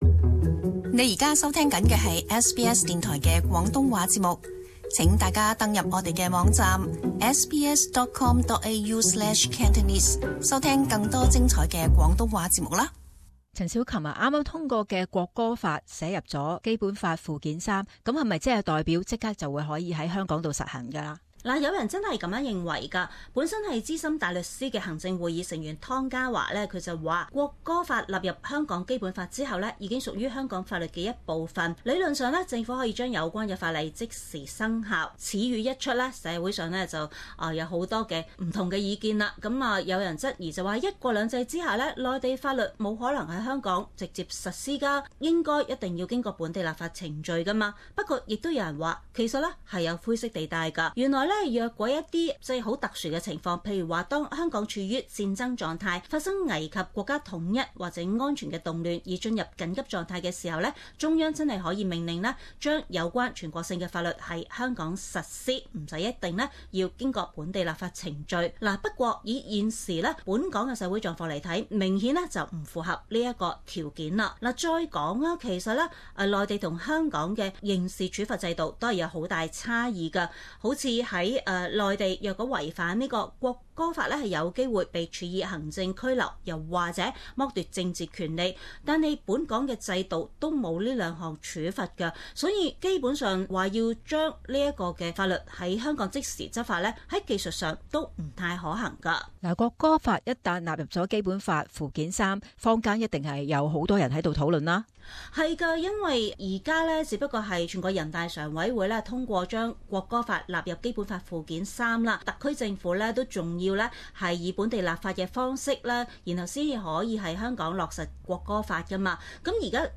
中港快訊